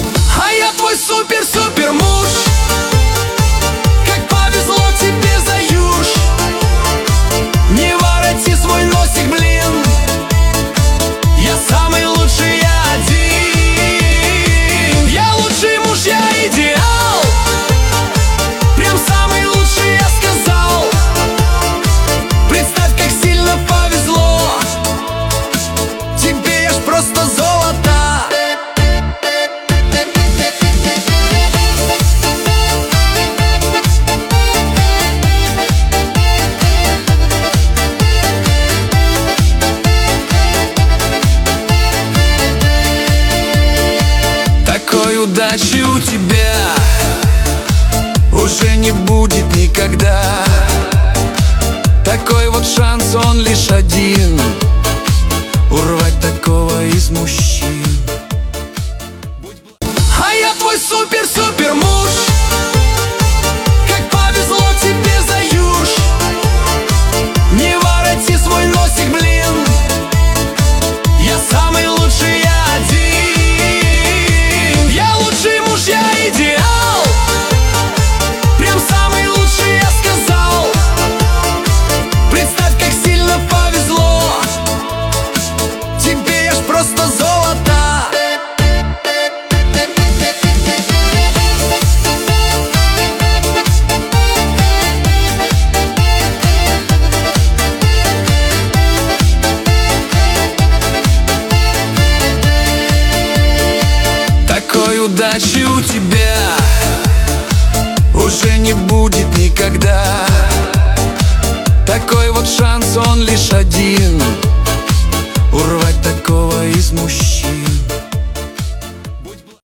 Качество: 320 kbps, stereo
Минусы песен 2025, Стихи, Нейросеть Песни 2025